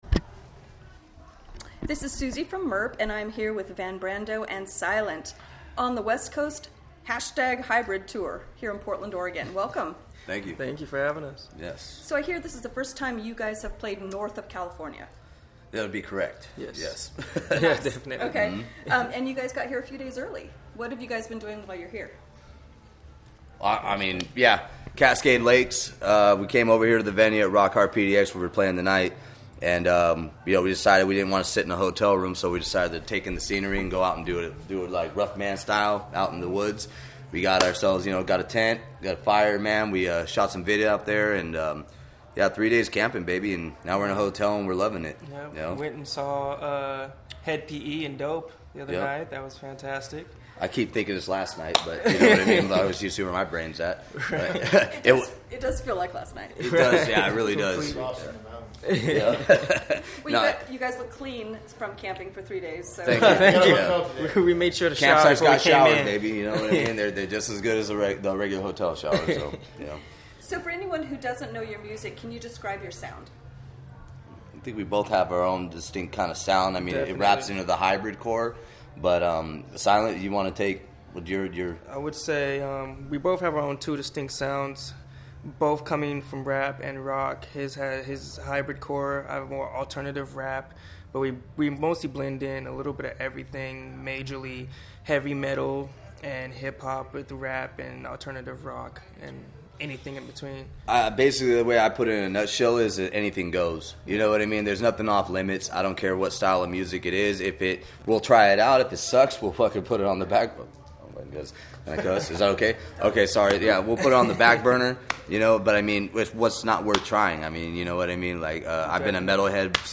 Portland, Oregon
Interviews - Audio